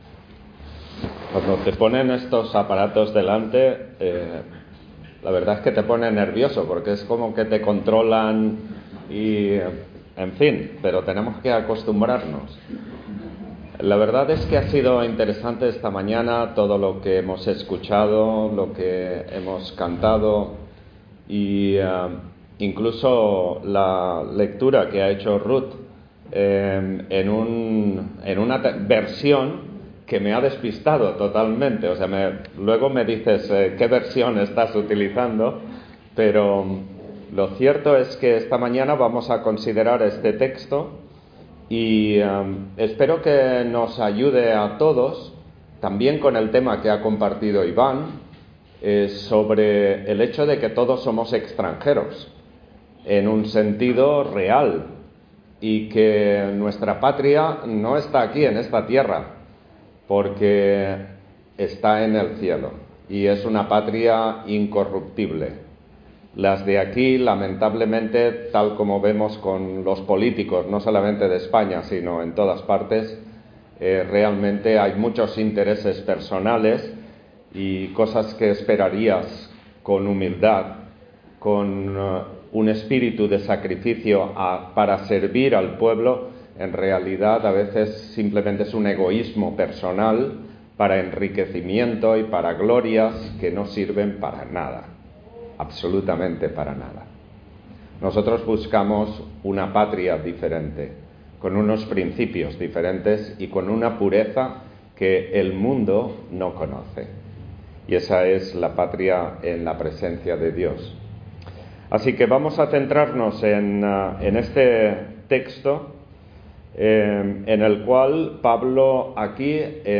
Service Type: Culto Dominical